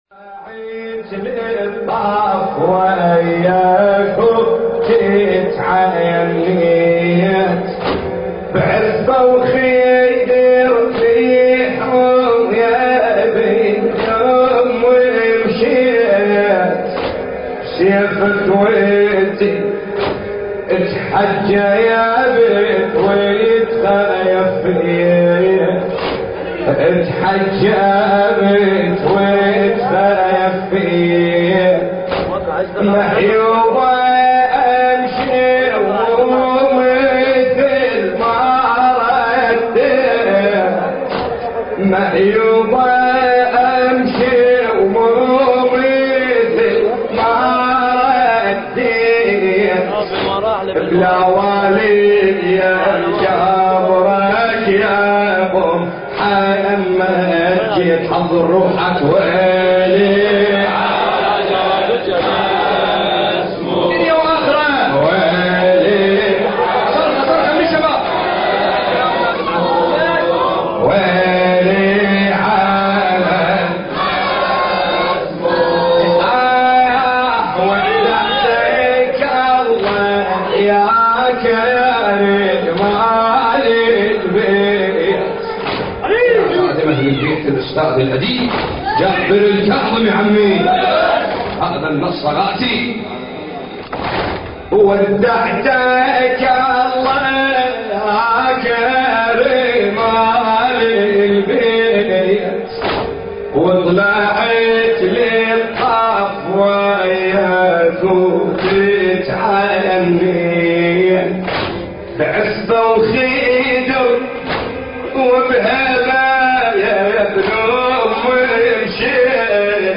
تأبين الإمام الحسن المجتبى عليه السلام